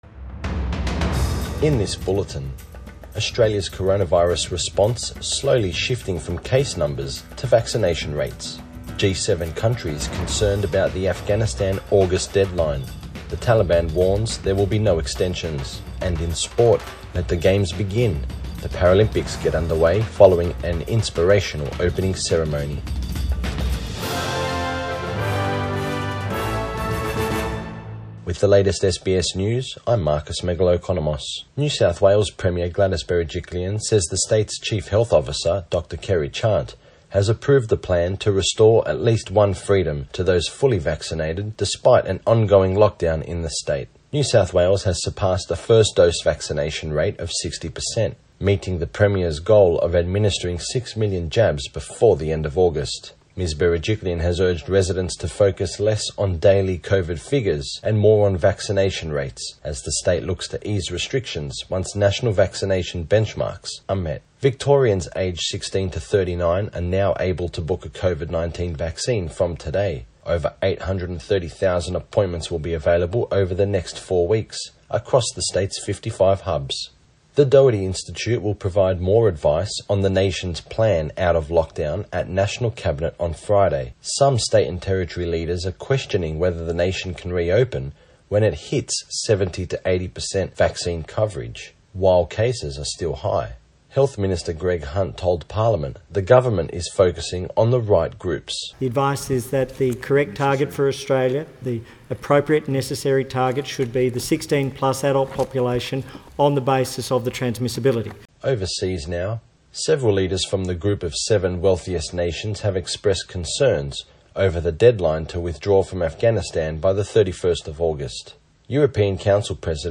AM bulletin 25 August 2021